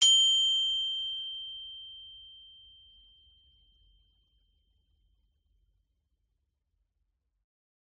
Звуки колокольчиков
Звон колокольчика уведомления